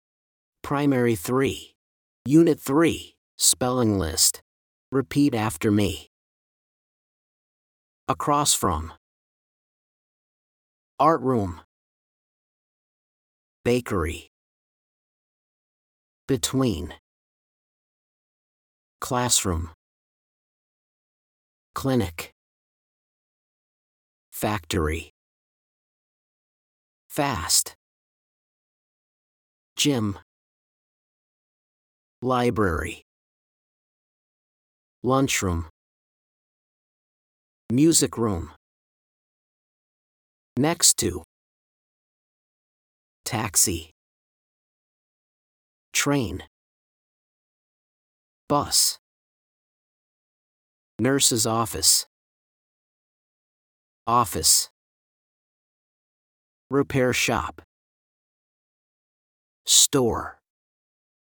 Listen and repeat after the teacher: